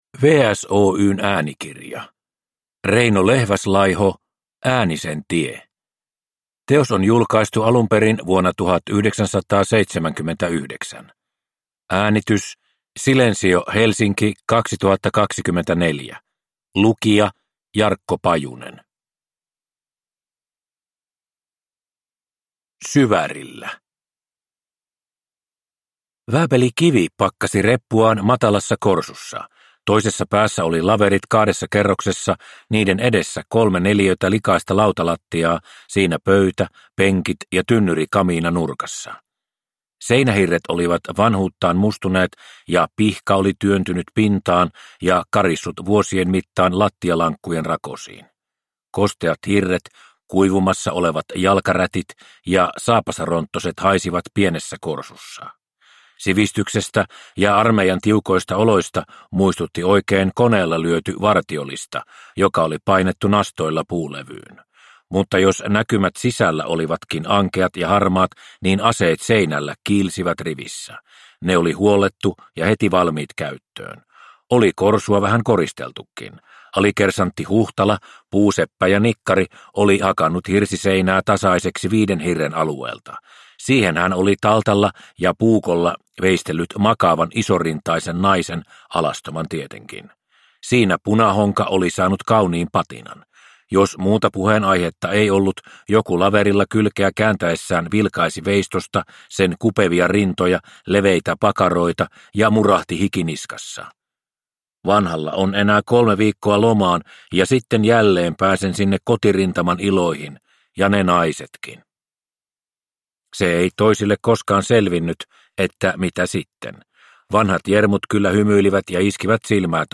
Äänisen tie – Ljudbok